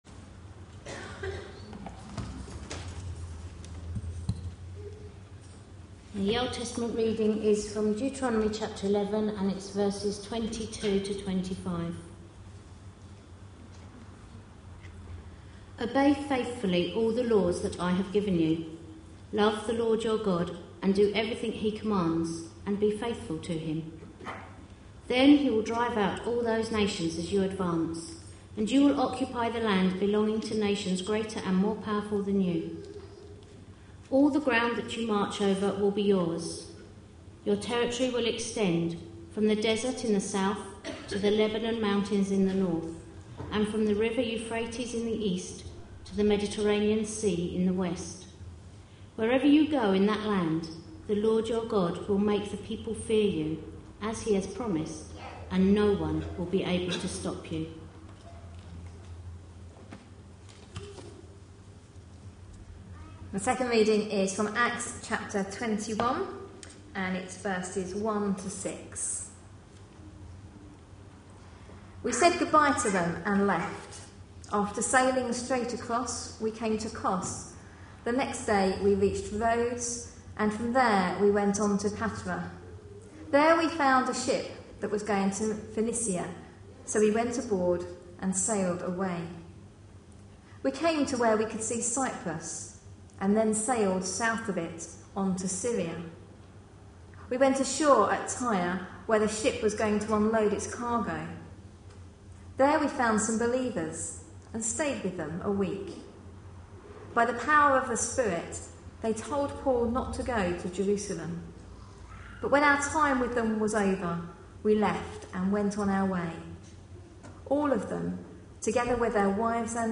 A sermon preached on 12th June, 2011, as part of our God at Work in..... series.